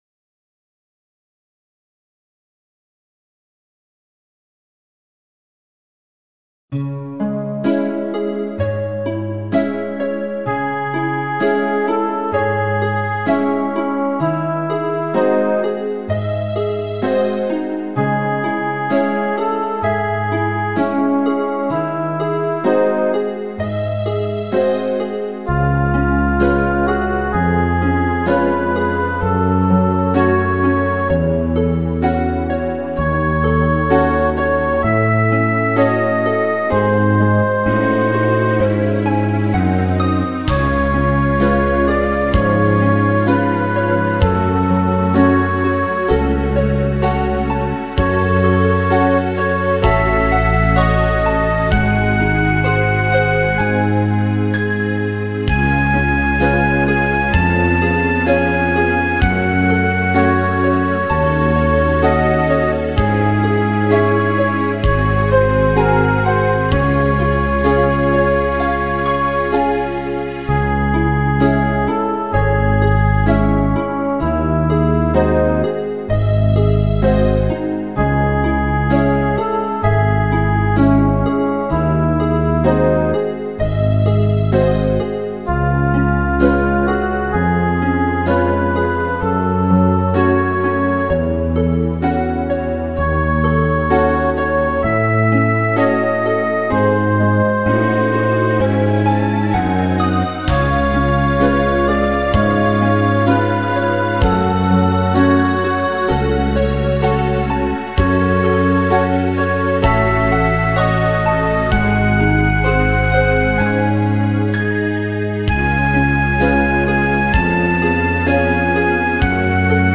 minus